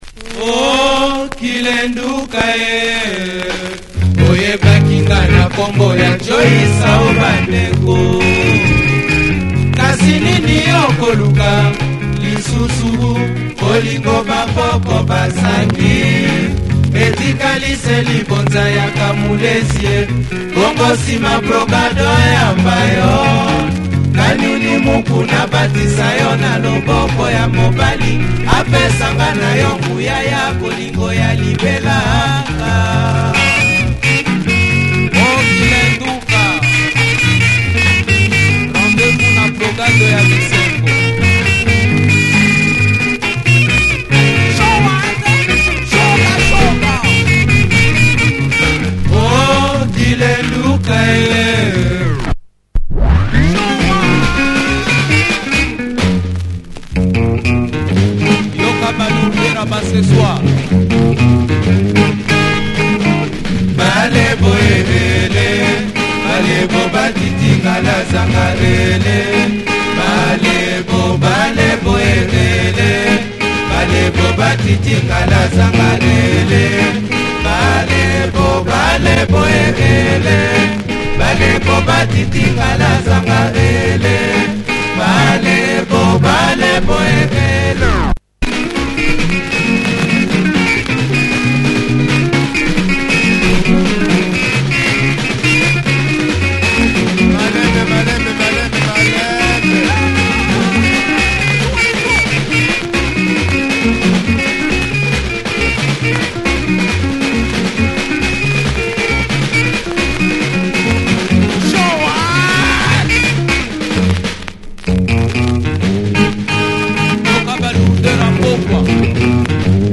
Nice arrangements on this Lingala track.